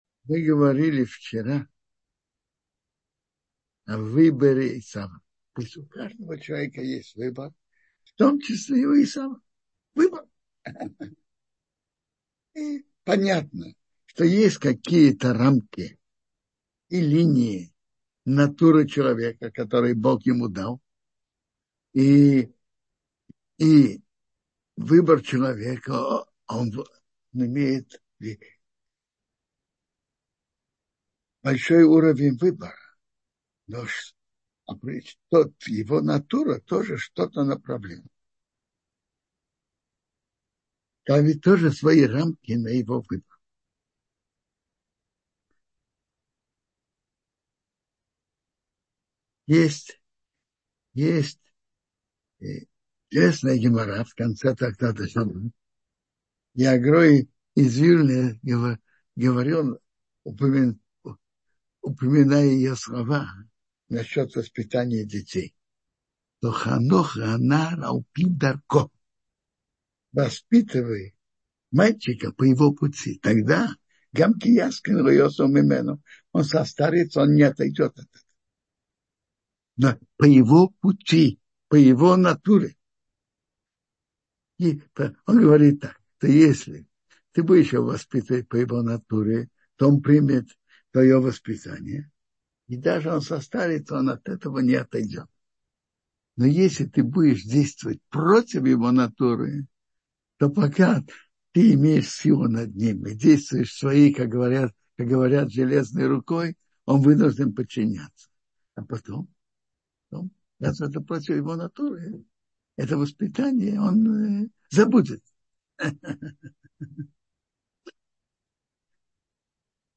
Благословение праотцов — слушать лекции раввинов онлайн | Еврейские аудиоуроки по теме «Недельная глава» на Толдот.ру